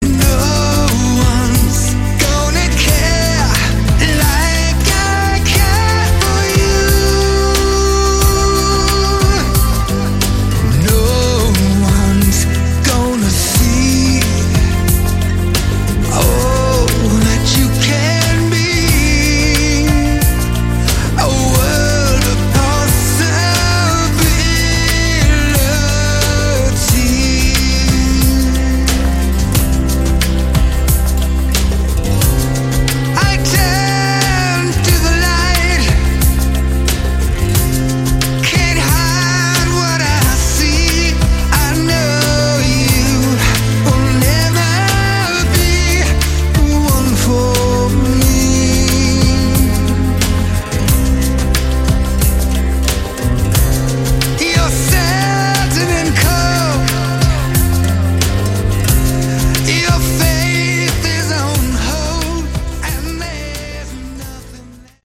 Category: Melodic Rock
lead & backing vocals
Additional acoustic guitars